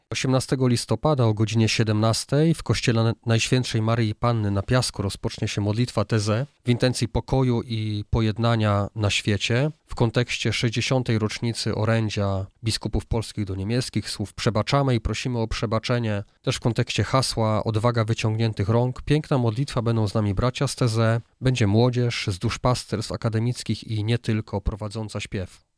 Jednym z punktów obchodów 60. rocznicy Orędzia biskupów polskich do niemieckich będzie ekumeniczna modlitwa ze śpiewami Taizé. O szczegółach mówi bp Maciej Małyga.